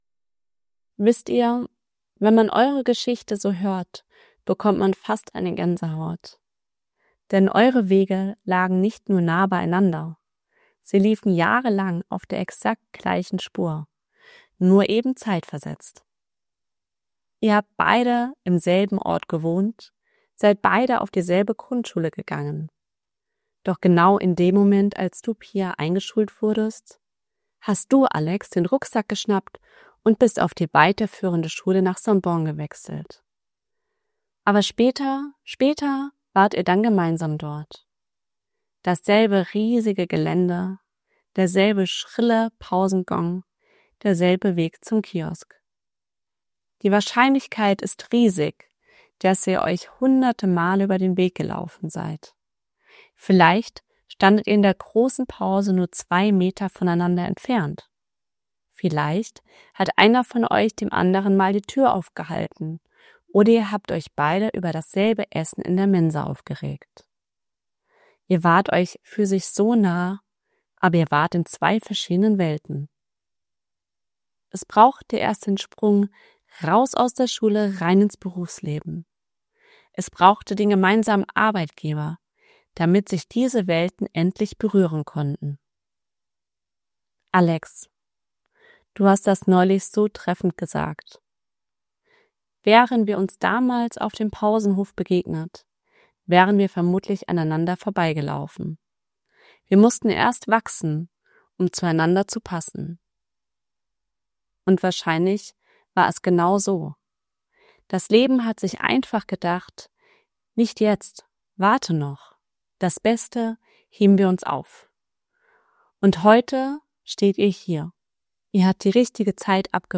Modern & Storytelling
hoerprobe-traurede-modern-storytelling-rhoen.mp3